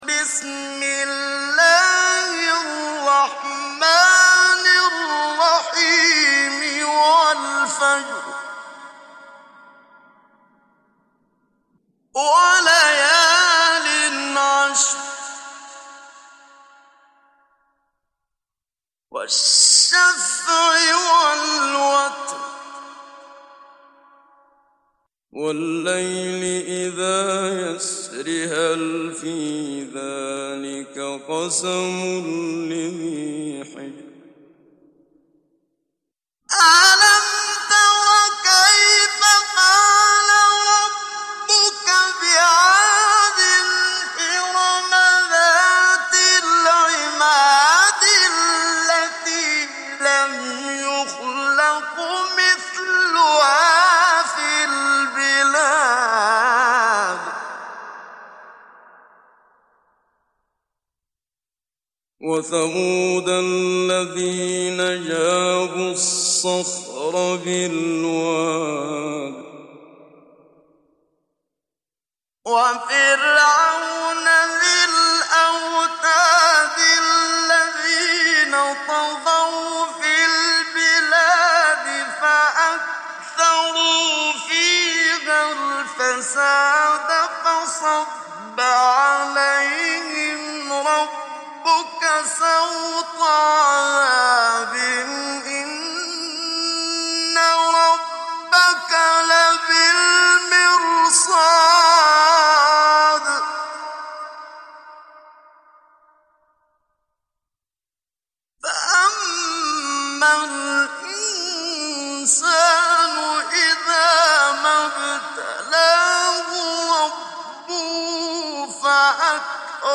تحميل سورة الفجر mp3 بصوت محمد صديق المنشاوي مجود برواية حفص عن عاصم, تحميل استماع القرآن الكريم على الجوال mp3 كاملا بروابط مباشرة وسريعة
تحميل سورة الفجر محمد صديق المنشاوي مجود